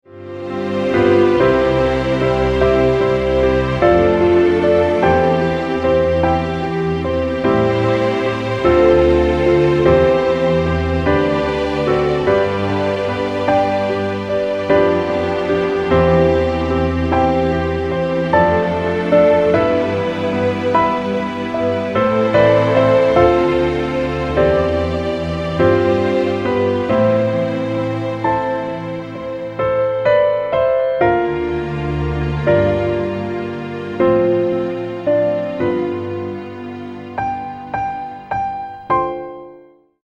Piano - Strings - Medium